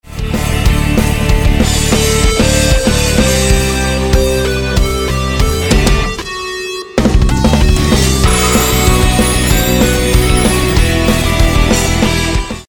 そんな感じでこの音を掛け合わせたものを作ってみました。
煌びやかな感じがしますよね。